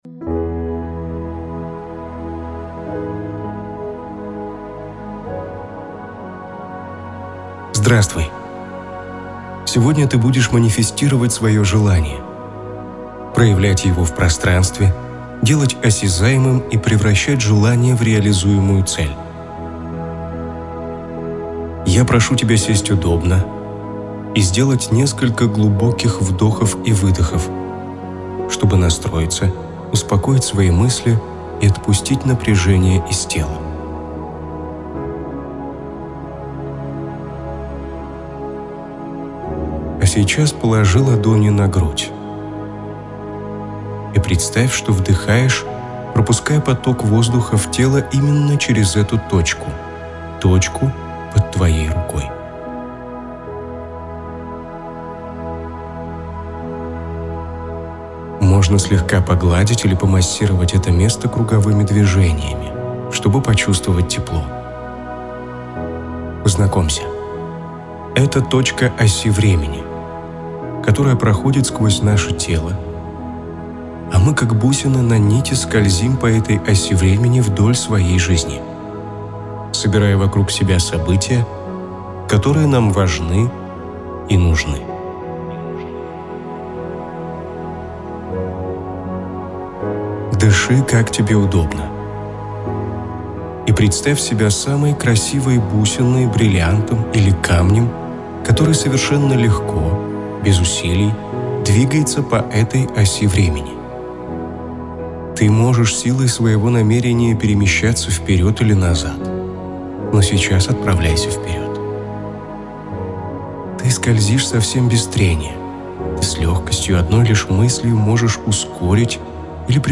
Уникальные аудио уроки и практики по медитации на исполнение желаний
ispolnenie-jelanii-praktika.mp3